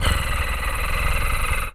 cat_purr_low_03.wav